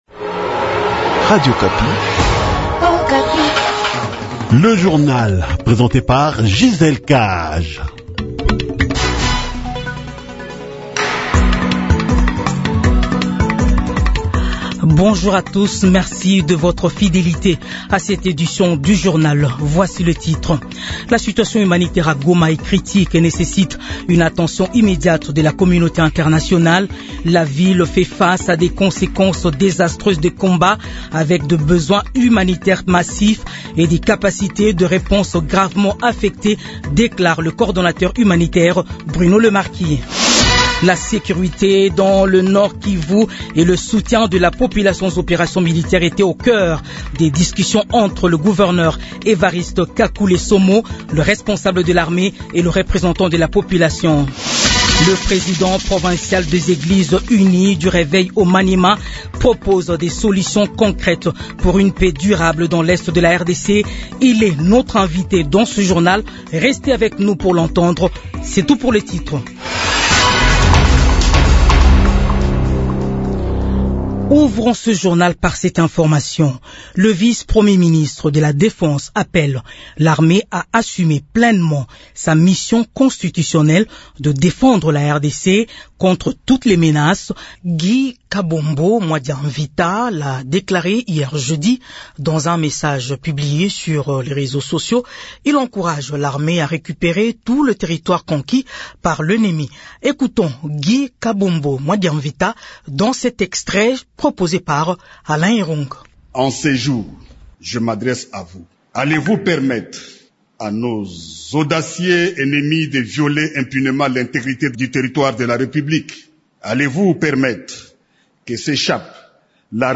Journal 06h-07h